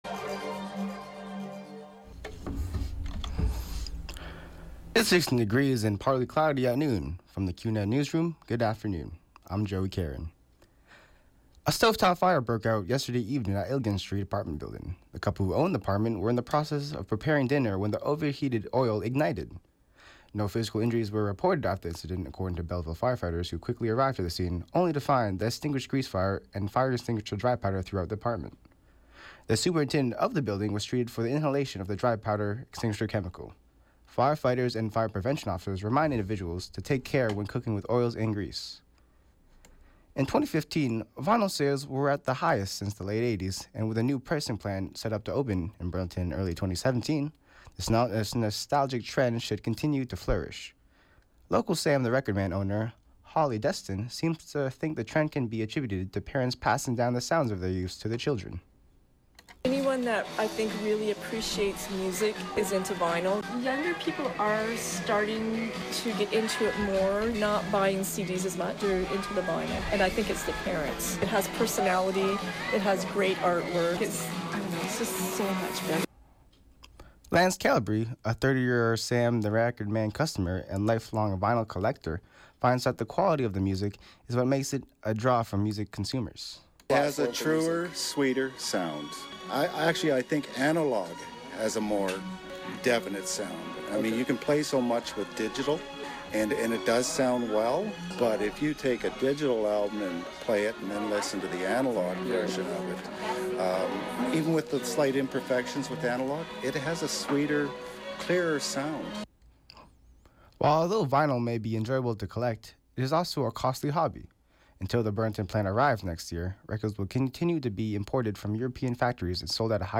91X FM Newscast – Wednesday, Nov. 2, 2016, noon